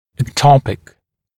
[ek’tɔpɪk][эк’топик]эктопированный, находящийся на неправильном месте